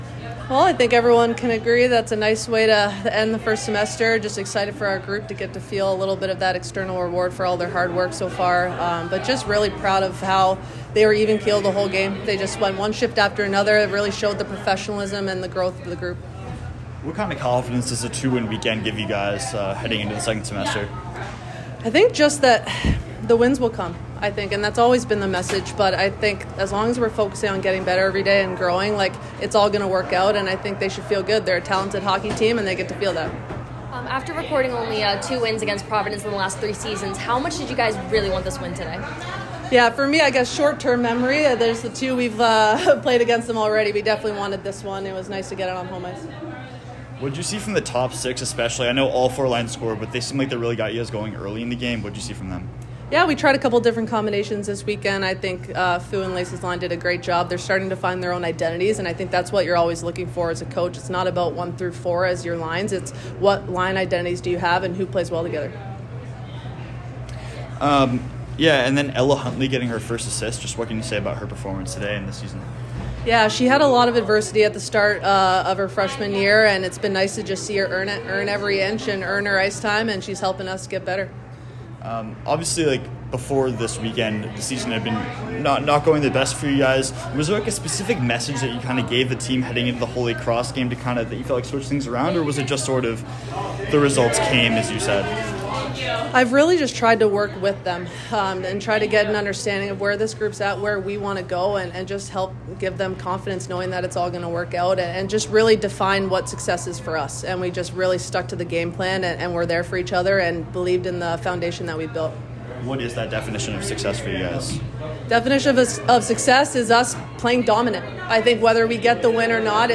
Women's Ice Hockey / Providence Postgame Interview